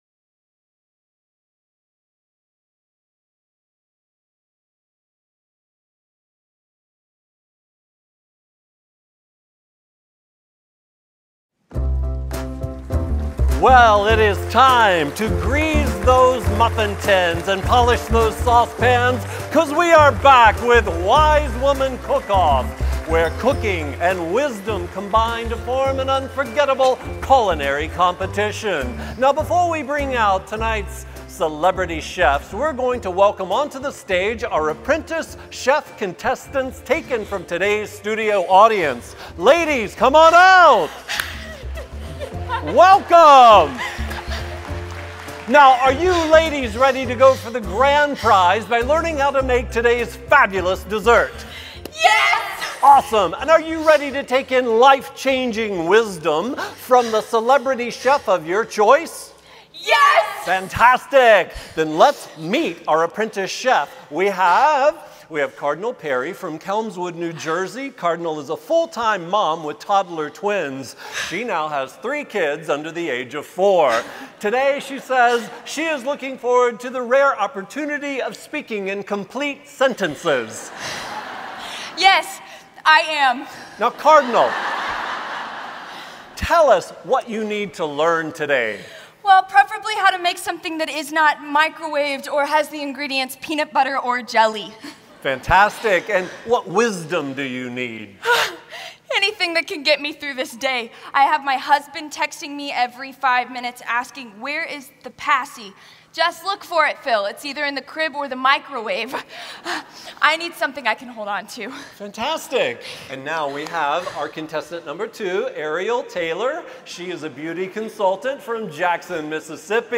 Message 2 (Drama): Wise Woman Bake Off
Revive '17 Adorned Conference - DVD Set